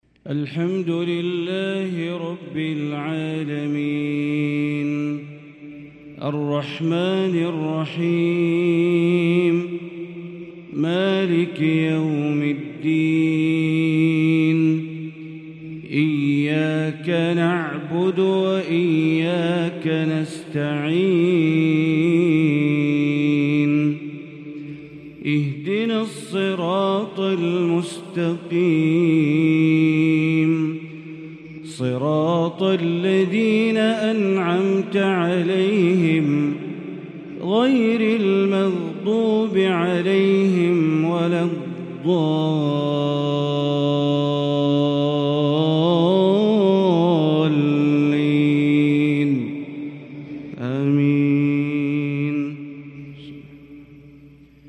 فجر السبت 8 محرم 1444هـ سورة المنافقون | Fajr prayar surah AlMunafiqun 7-8-2022 > 1444 🕋 > الفروض - تلاوات الحرمين